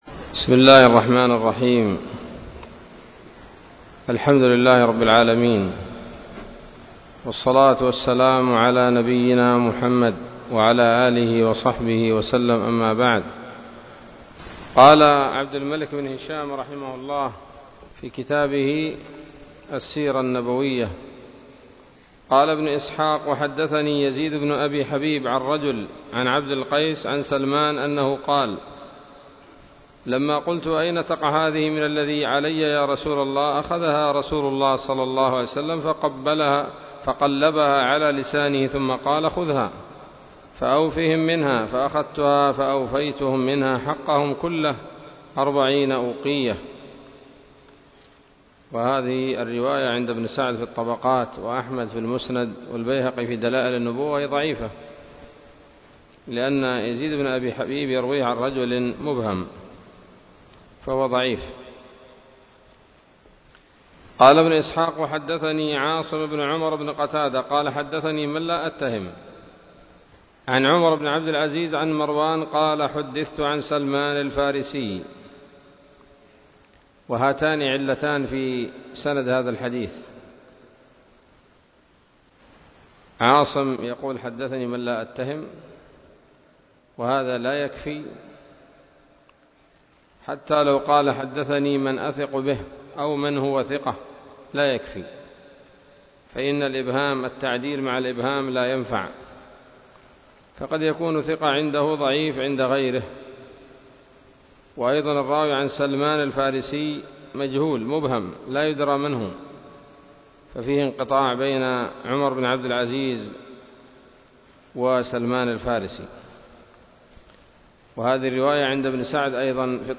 الدرس العشرون من التعليق على كتاب السيرة النبوية لابن هشام